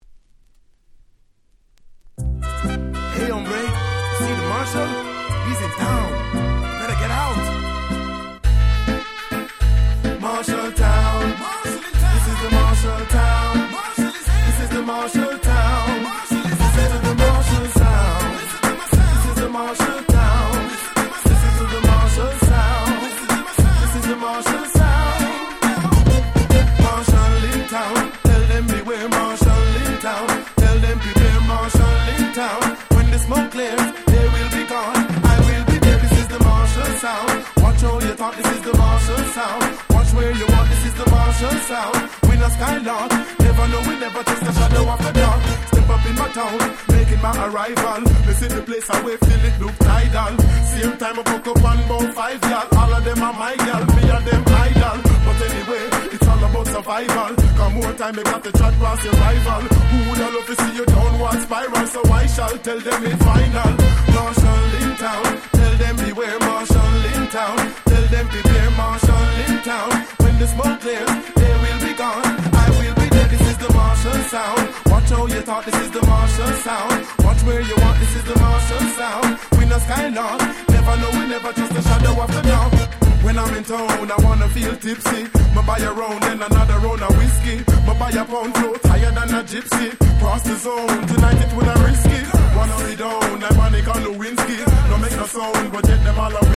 03' Smash Hit Dancehall Reggae !!